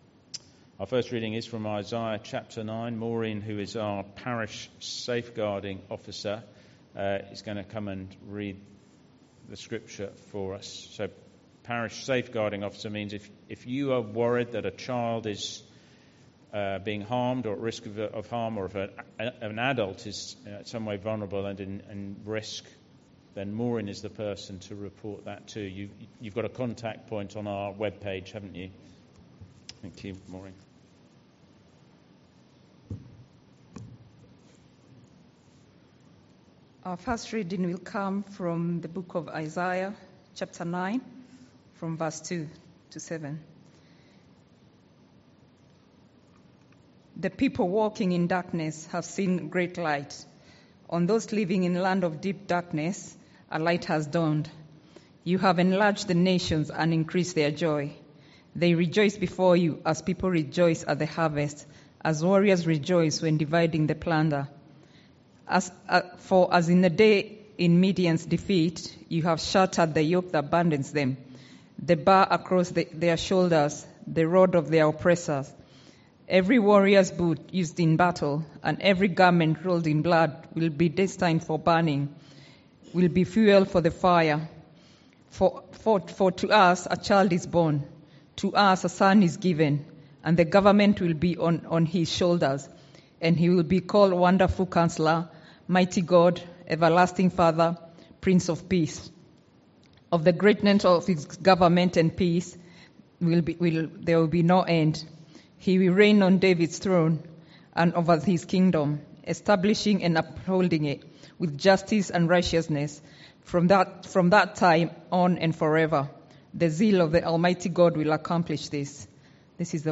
Sermons – Dagenham Parish Church